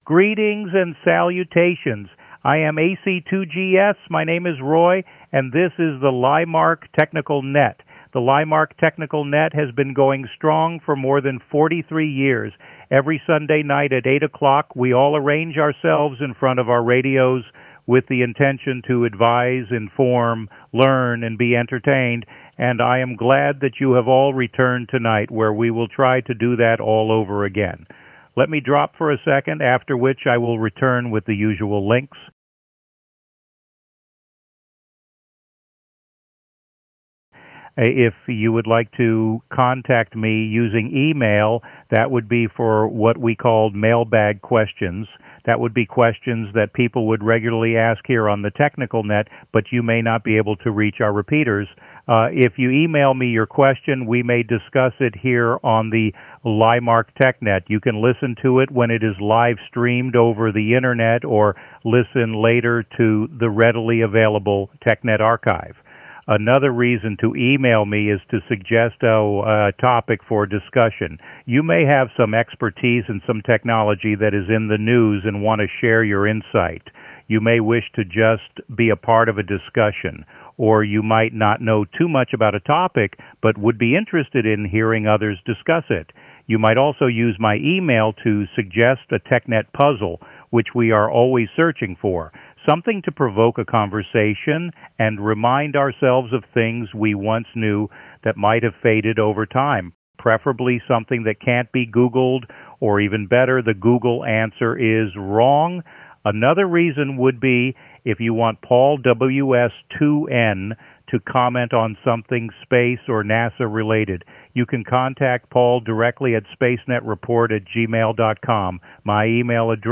Computer Generated TechNet Intro for Today, Without Repeater or AllStar Artifact:
LIMARC-TechNet-Into-Synthesisized-September-3-2023.mp3